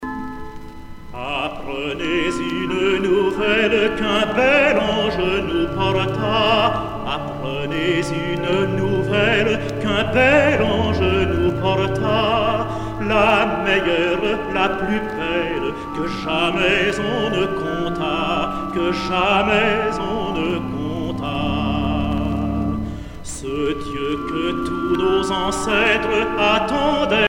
circonstance : Noël, Nativité